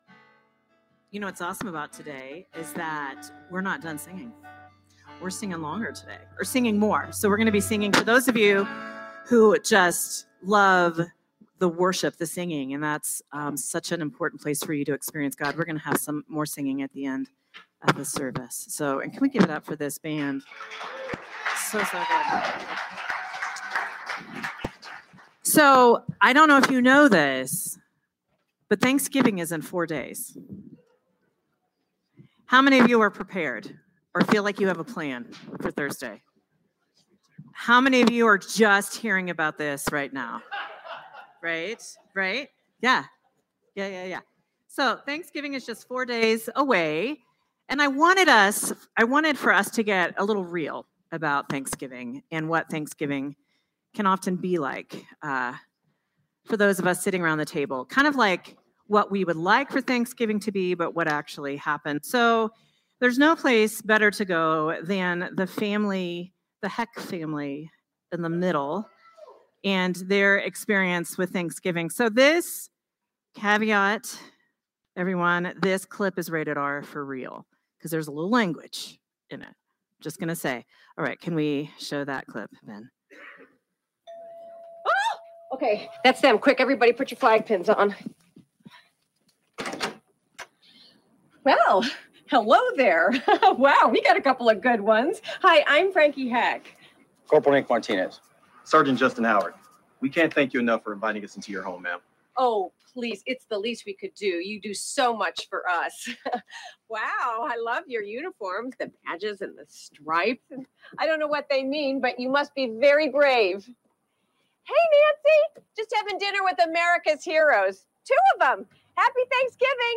Sermon from Celebration Community Church on November 23, 2025